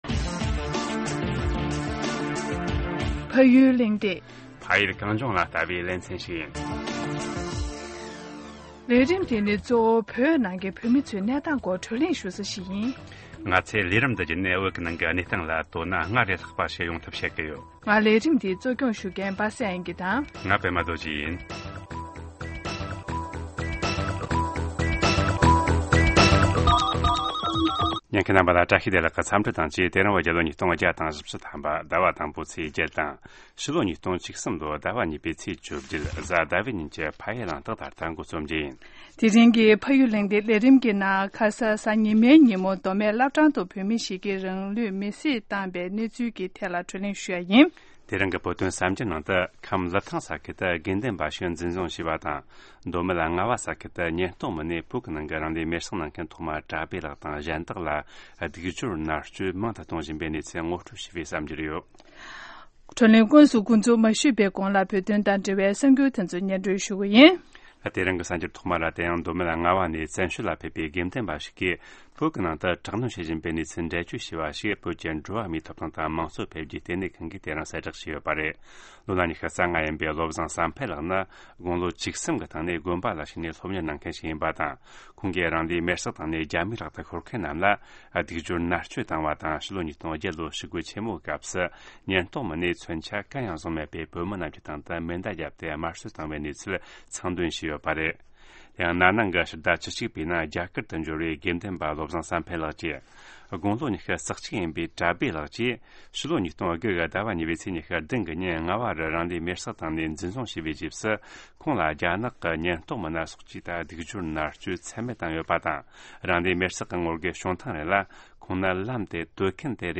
བགྲོ་གླེང་ཞུས་པ་ཞིག་གསན་གནང་གི་རེད།